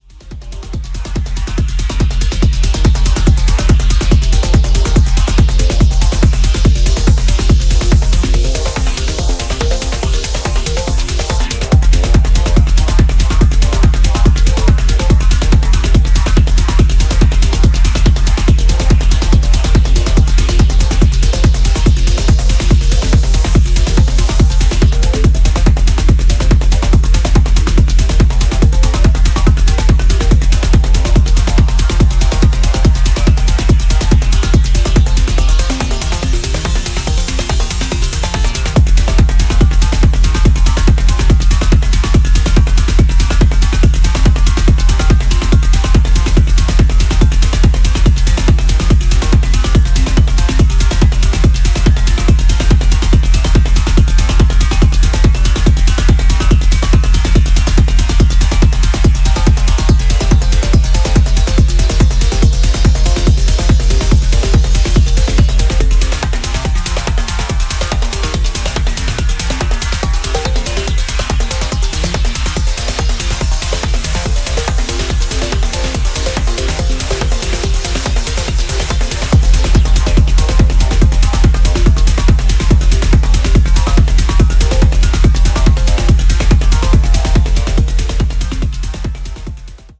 フロア完全対応なディープ・テクノ推薦盤。